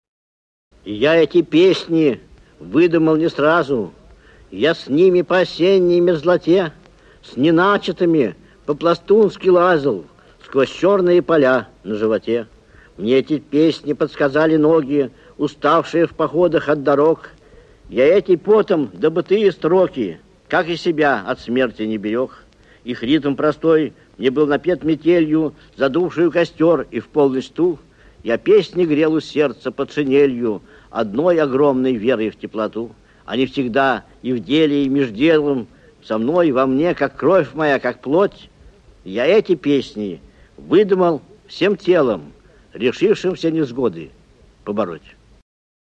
1. «Евгений Винокуров – Я эти песни написал не сразу… (читает автор)» /
Vinokurov-Ya-eti-pesni-napisal-ne-srazu.-chitaet-avtor-stih-club-ru.mp3